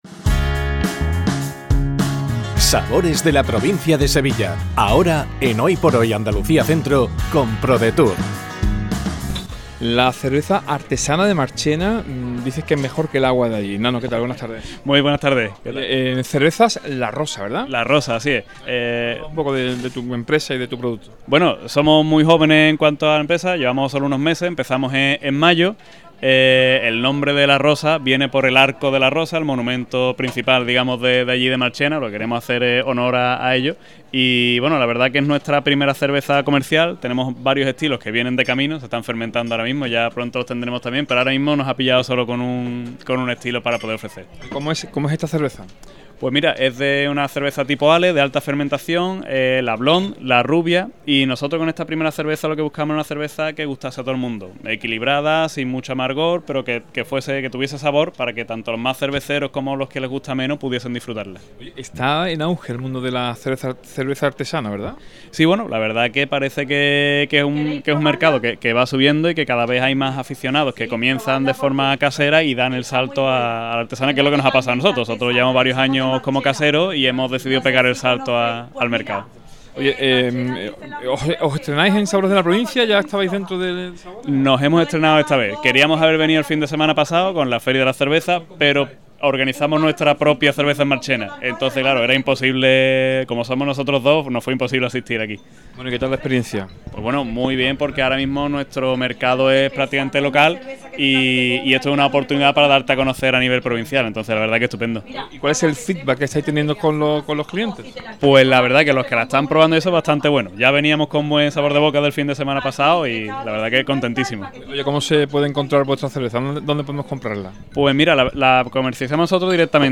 ENTREVISTA | Cerveza La Rosa